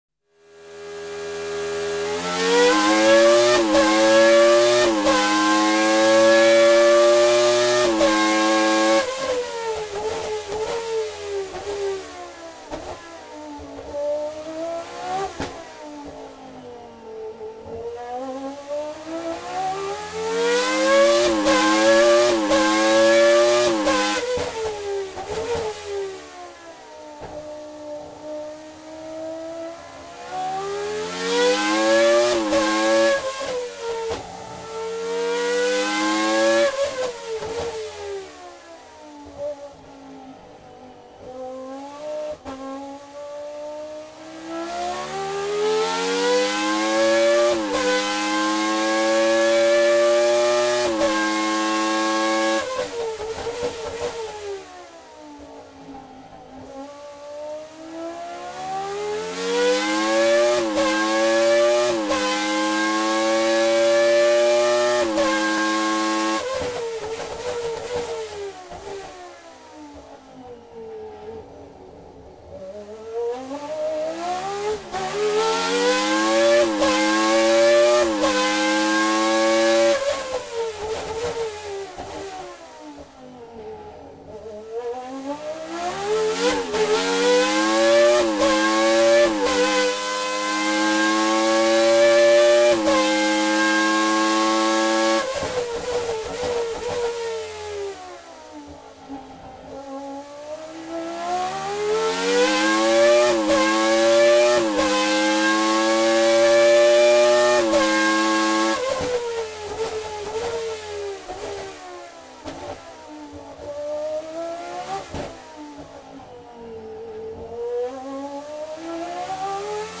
Il motore Ferrari al banco prova :  3 minuti di sgassate e cambiate che metteranno a dura prova le vostre casse.
banco_prova.mp3